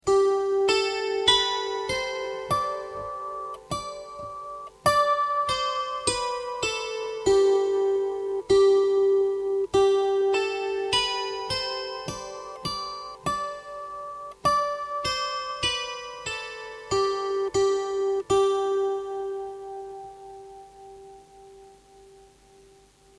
VIISIKIELINEN KANTELE